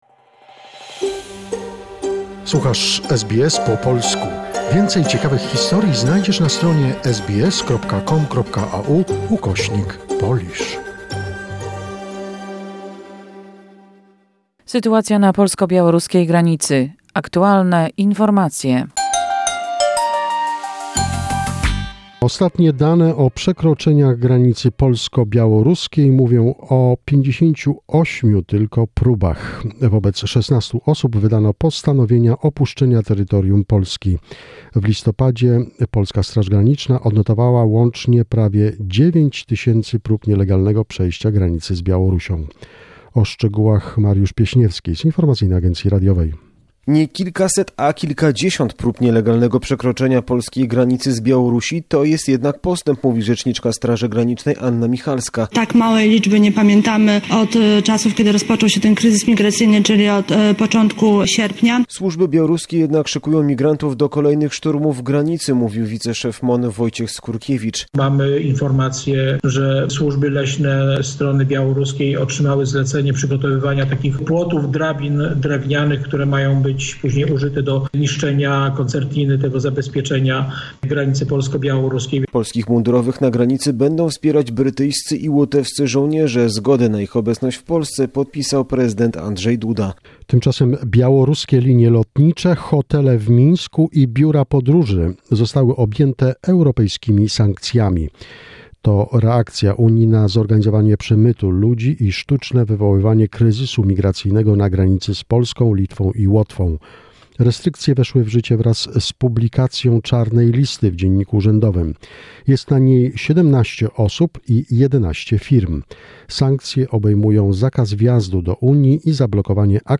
The most recent information and events regarding the Poland-Belarus situation, a short report prepared by SBS Polish.